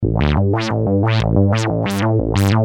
Free MP3 vintage Sequential circuits Pro-1 loops & sound effects 1